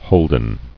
[hold·en]